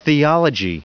Prononciation du mot theology en anglais (fichier audio)
Prononciation du mot : theology